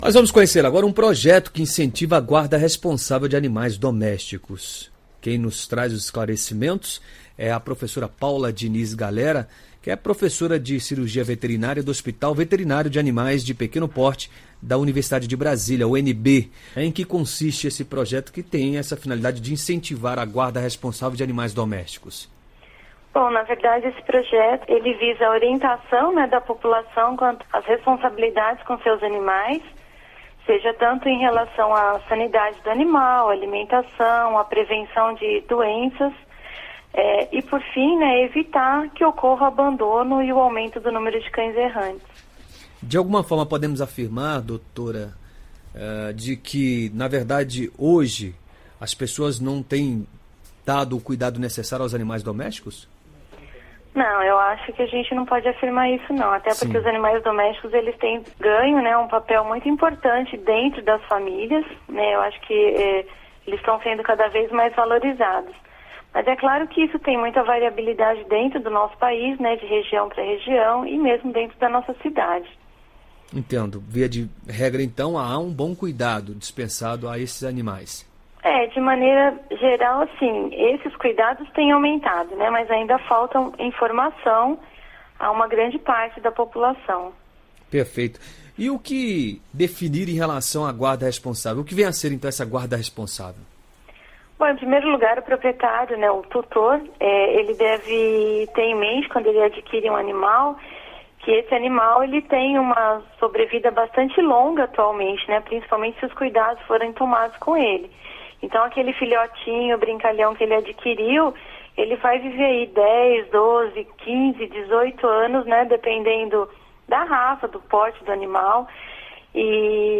Entrevista: Entenda o que é guarda responsável de animais domésticos